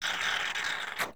plunger.wav